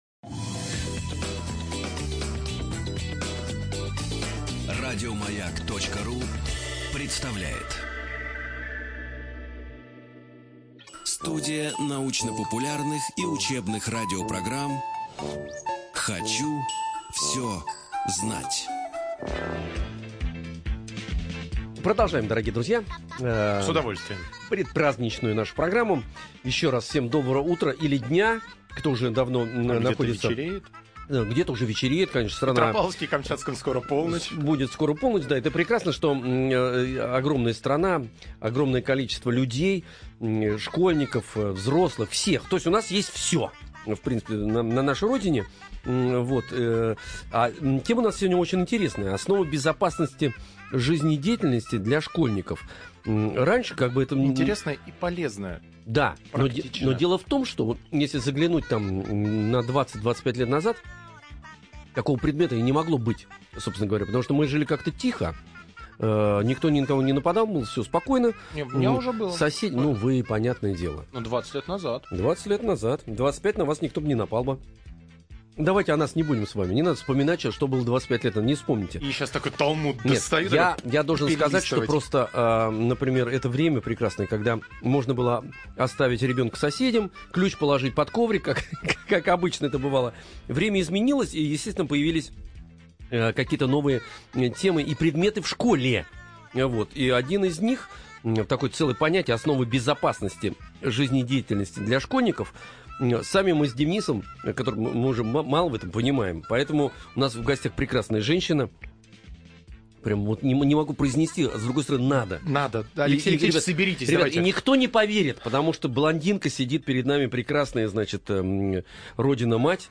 Студия звукозаписиРадио Маяк